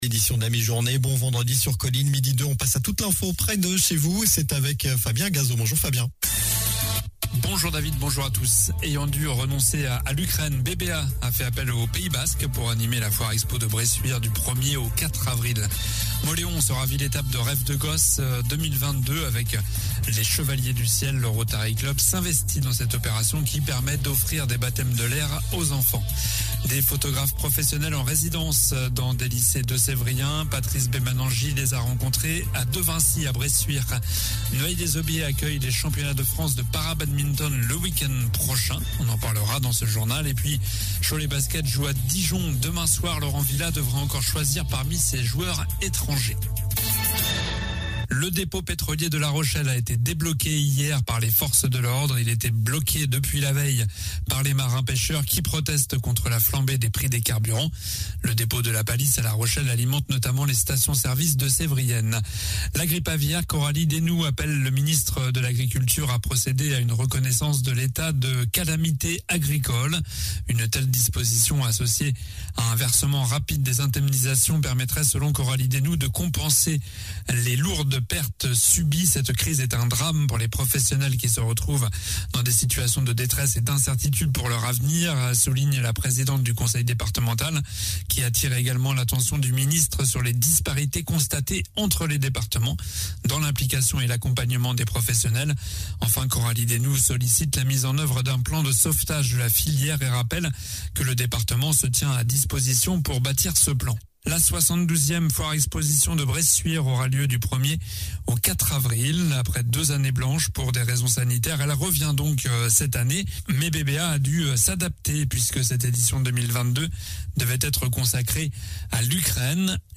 Journal du vendredi 18 mars (midi)